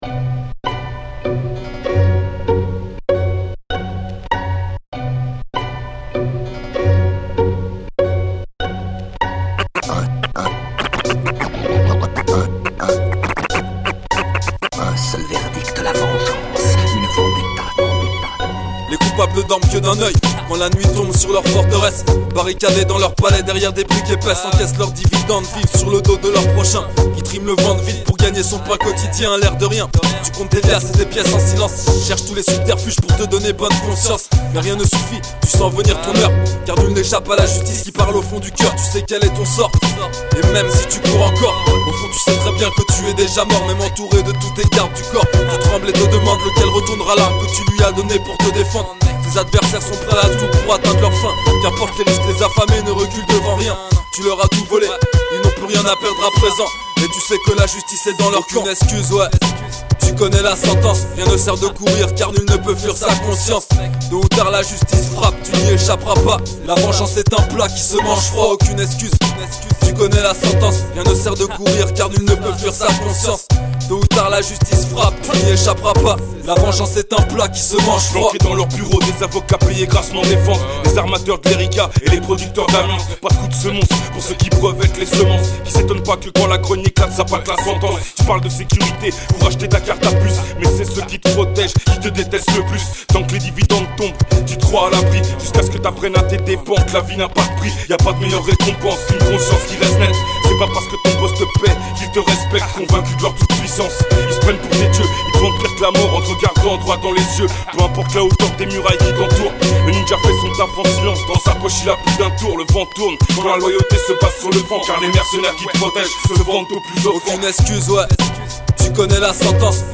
instru & scratch